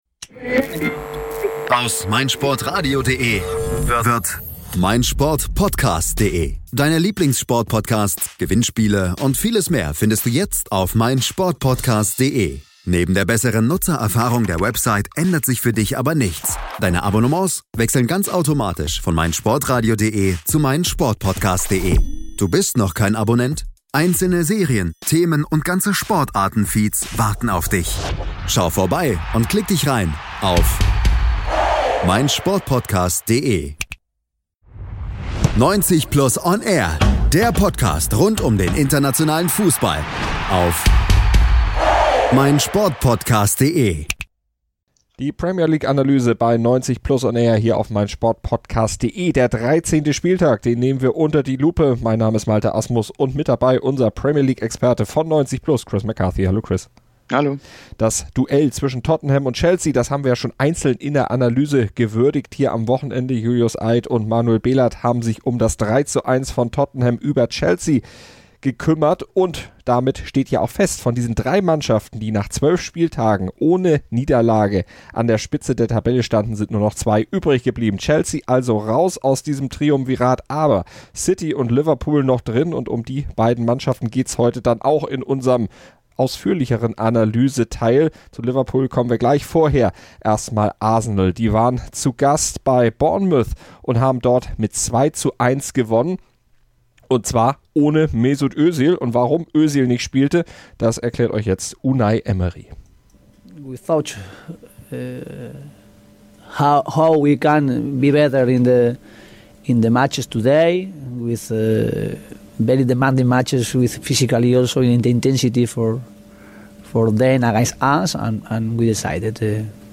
Beide Teams siegten deutlich, aber hatten trotzdem unter der Länderspielpause gelitten, wie beide Trainer im O-Ton erklären.